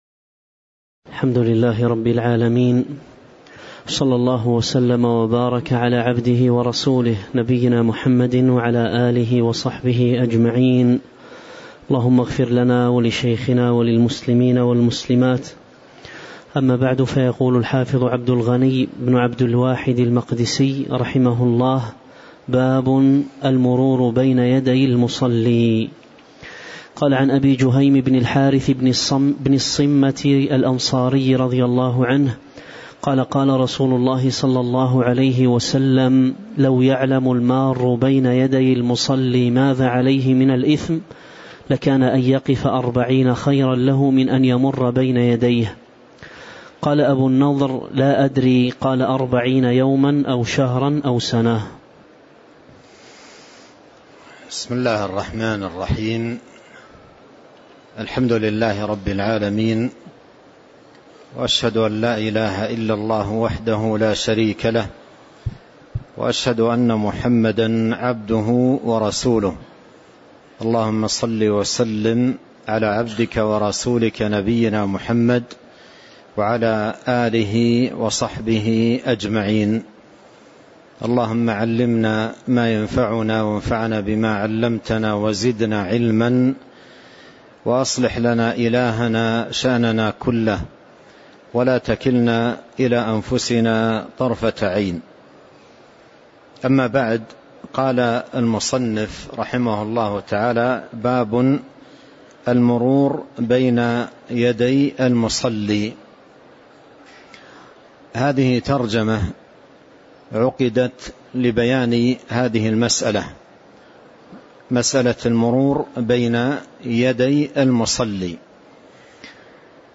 تاريخ النشر ١٢ ربيع الثاني ١٤٤٤ هـ المكان: المسجد النبوي الشيخ